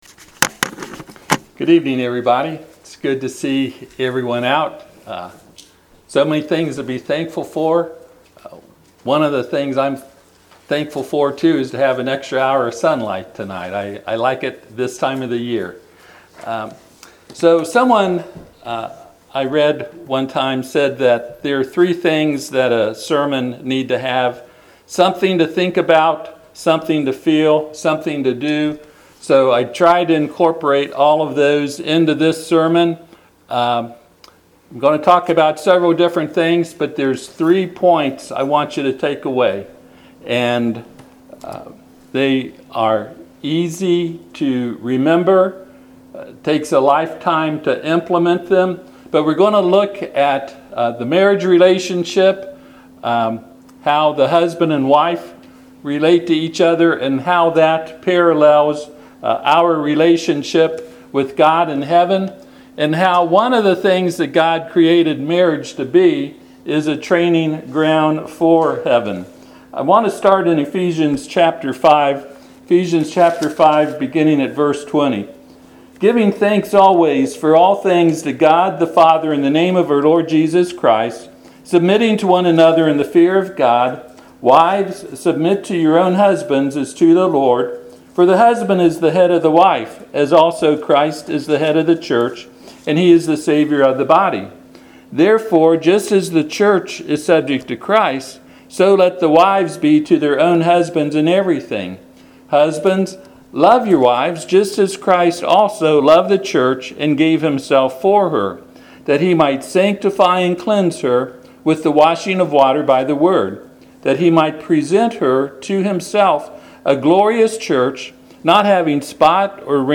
Ephesians 5:24-25 Service Type: Sunday PM Ephesians 5:24-25 Therefore